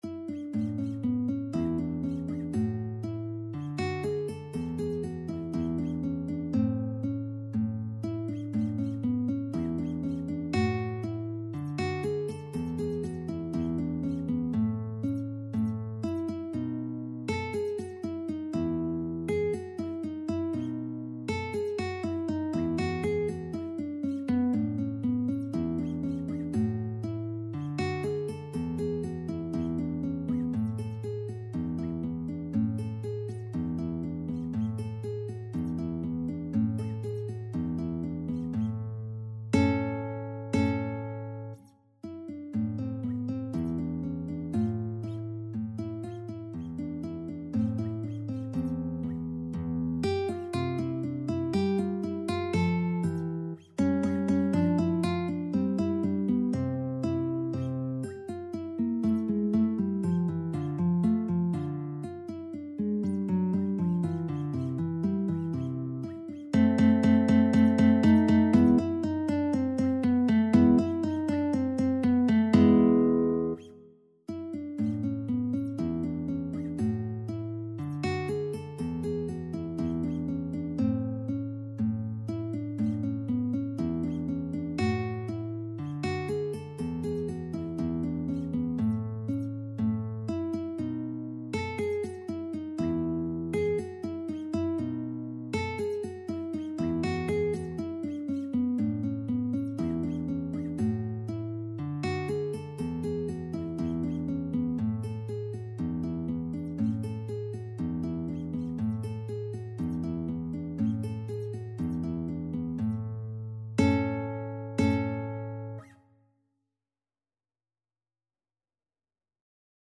Free Sheet music for Guitar
Guitar 1 (Standard Notation)Guitar 2 (TAB)
E3-A5
A major (Sounding Pitch) (View more A major Music for Guitar )
2/4 (View more 2/4 Music)